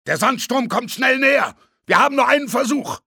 In addition to recurring voices from the previous instalments, actor Ben Becker joins the fray in the role of protagonist Soap MacTavish, rendering the gripping story even more genuine and frenzied with his brilliant performance as the game's hero.